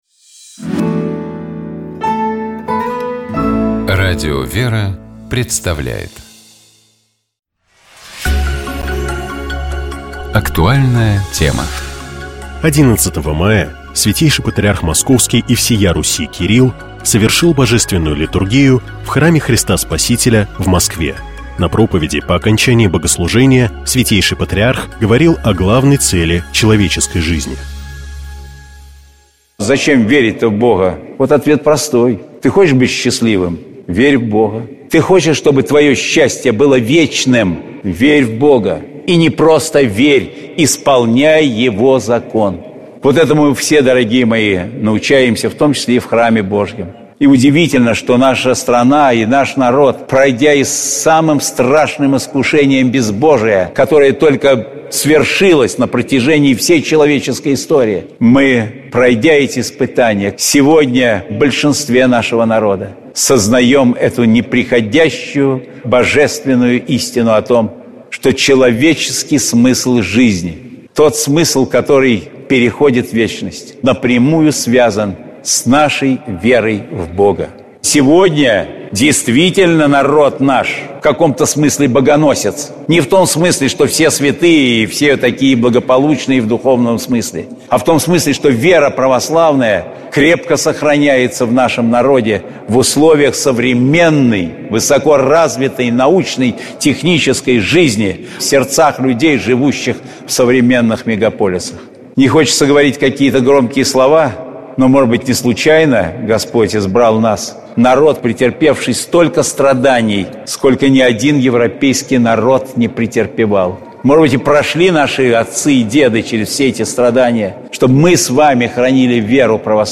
Каждую пятницу ведущие, друзья и сотрудники радиостанции обсуждают темы, которые показались особенно интересными, важными или волнующими на прошедшей неделе.